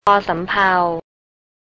พอ-สำ-เพา
por sum-pao
pan (low tone)